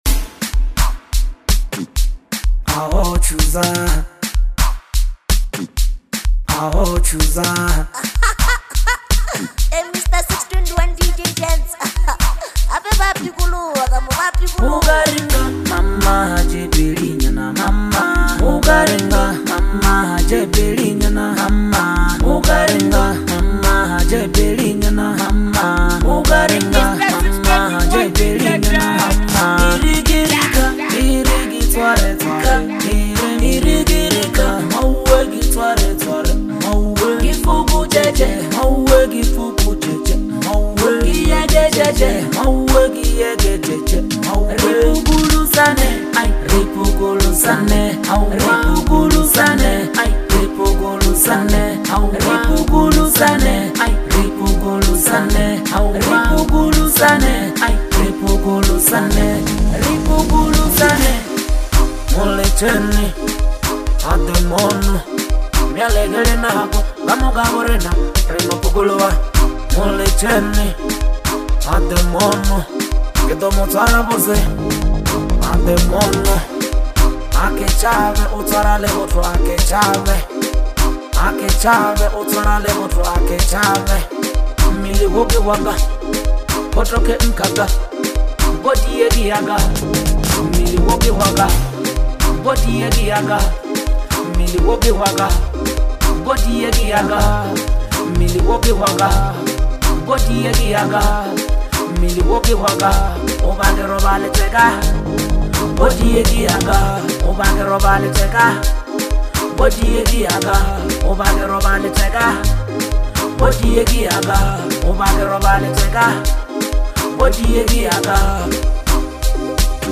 With its vibrant beat, catchy hook, and bold lyrical content